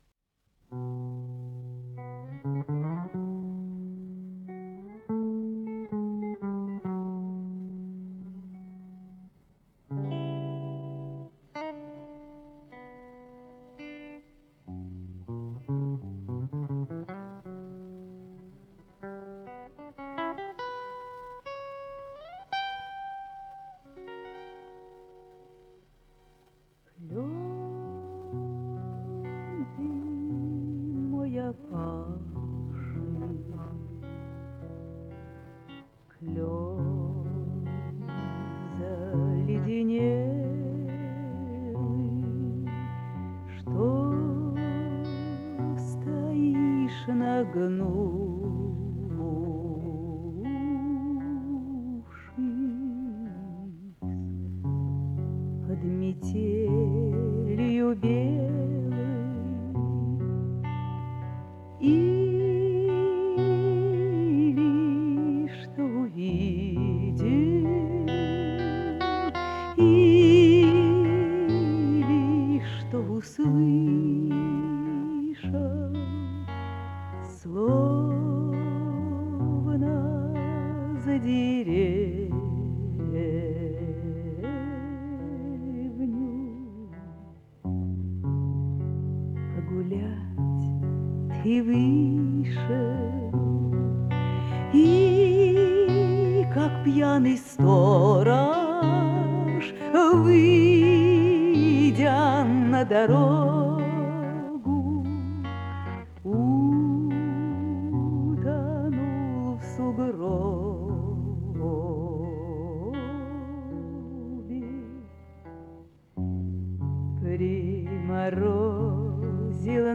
Вроде бы нормальная запись.